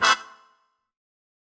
GS_MuteHorn-Amin9.wav